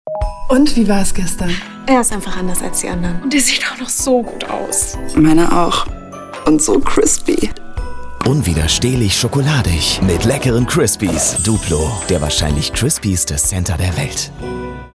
Commercial (Werbung), Off